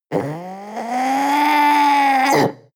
Gemafreie Sounds: Panther